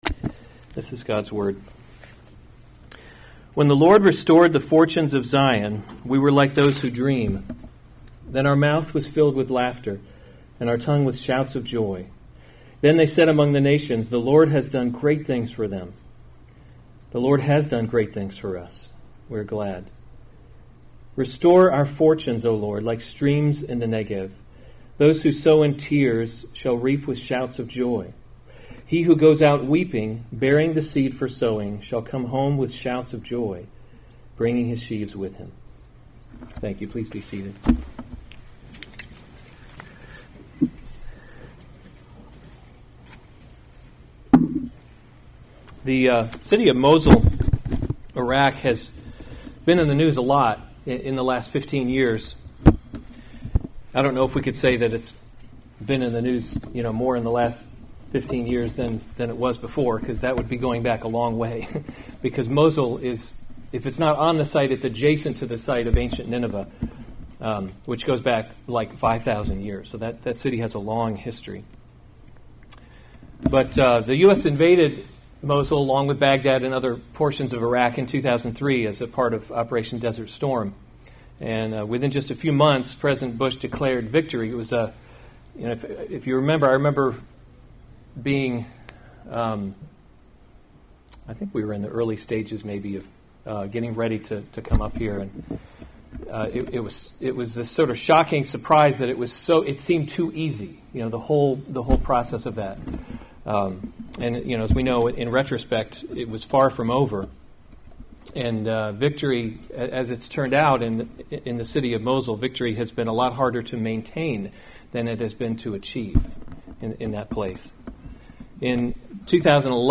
September 1, 2018 Psalms – Summer Series series Weekly Sunday Service Save/Download this sermon